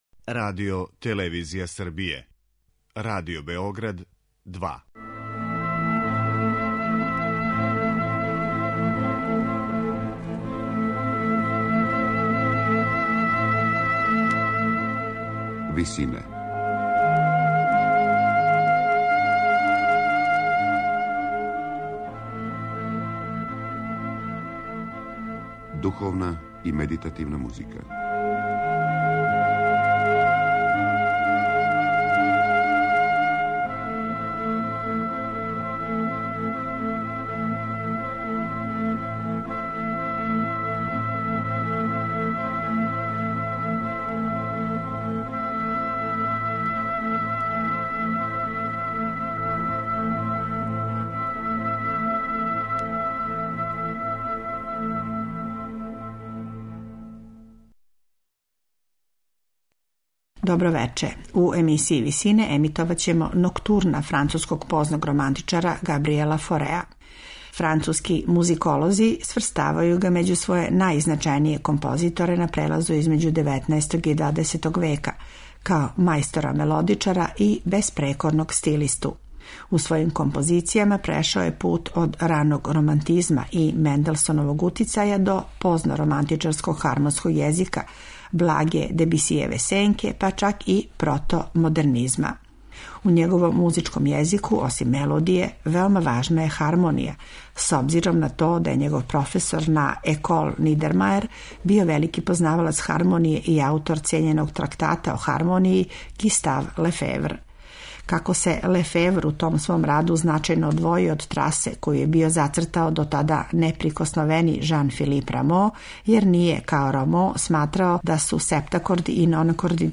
Свира пијаниста Жан Филип Којар.